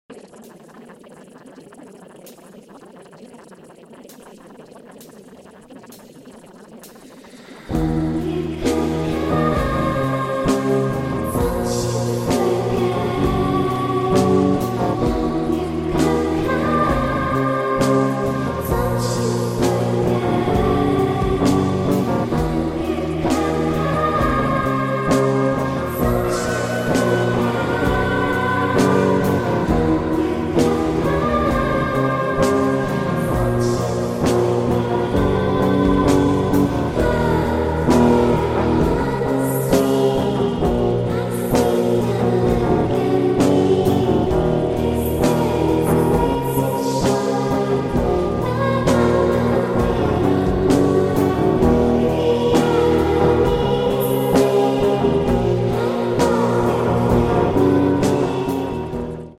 ℐnfos : – 100% confiável – ouça por pelo menos 3 dias, quantas vezes quiser – fones de ouvido são opcionais – já é seu, os resultados já apareceram 𝒞ontem : – +500 afirmações para: pele, corpo, beleza, atração e mais; repetidas em camadas diferentes. – frequência de 440Hz para potencialização.